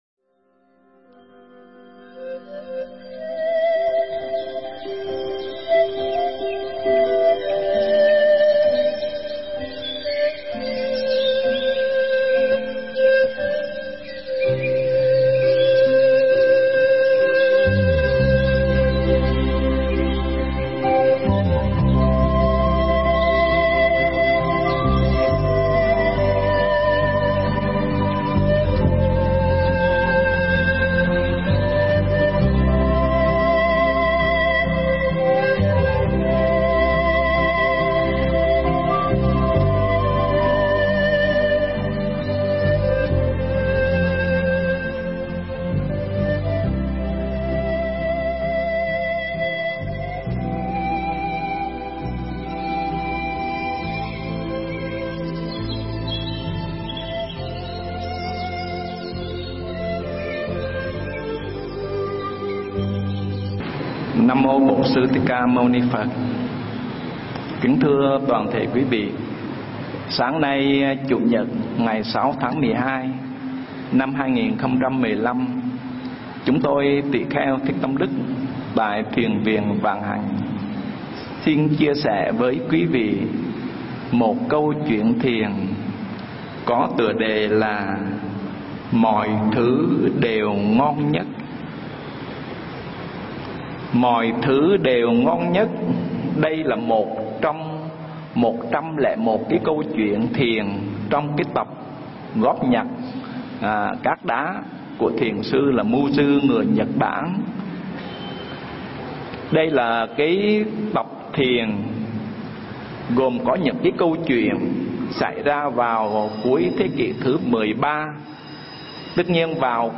Nghe Mp3 thuyết pháp Mọi Thứ Đều Ngon Nhất